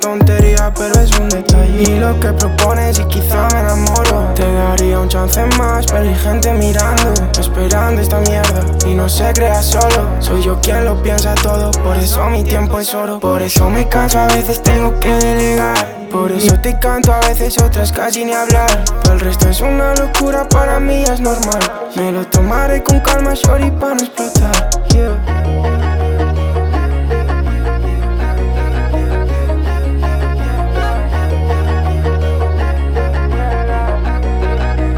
Жанр: Хип-Хоп / Рэп / Альтернатива
Hip-Hop, Rap, Alternative Rap